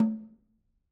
Snare2-HitNS_v3_rr1_Sum.wav